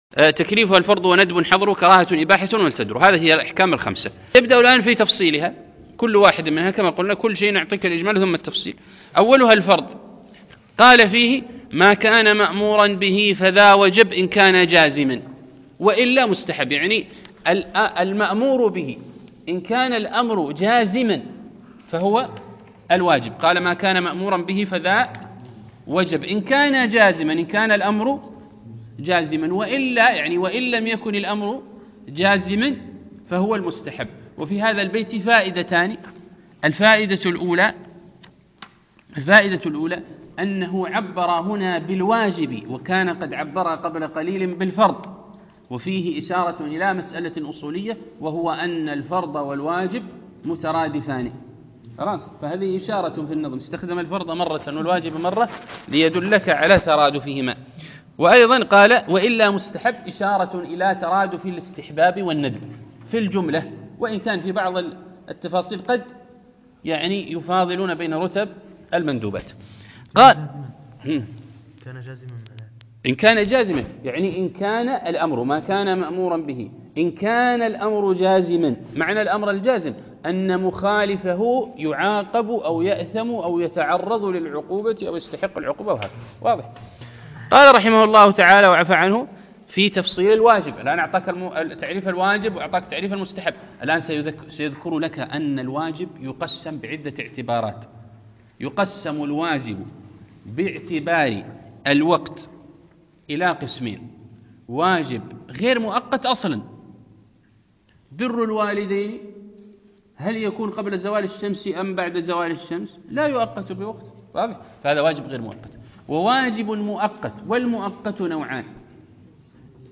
عنوان المادة الدرس (3) شرح النظم المعسول في تعليم الأصول